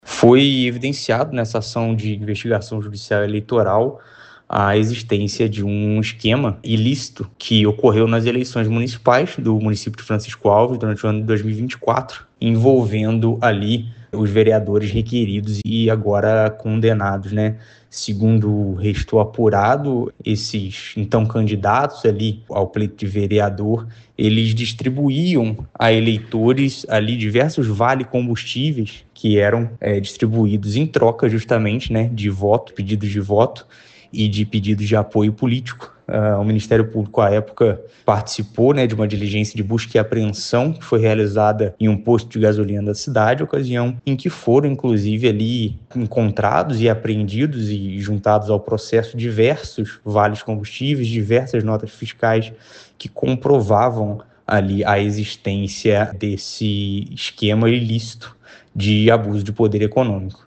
Ouça o que diz o promotor de Justiça Filipe Rocha e Silva: